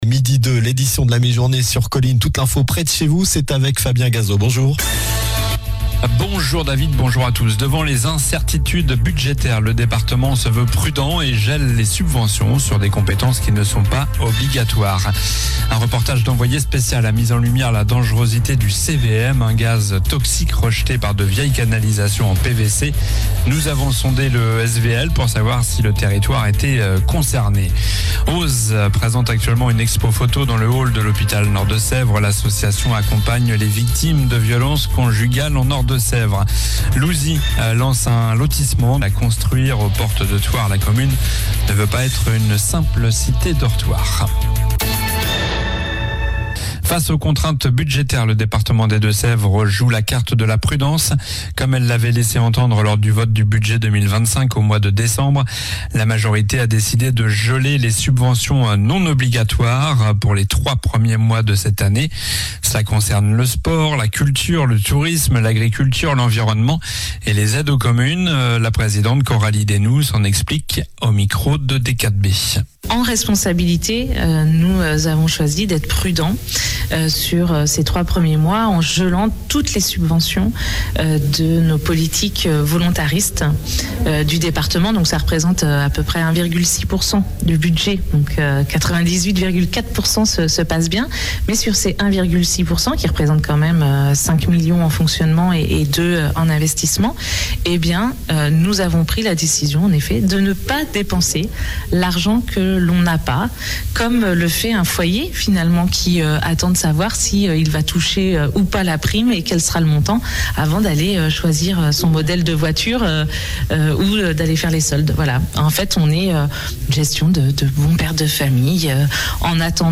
Journal du mardi 21 janvier (midi)